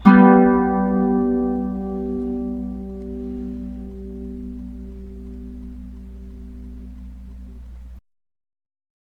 • The Bajo Sexto is a twelve-string guitar.
A chord